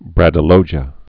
(brădə-lōjə, -jē-ə)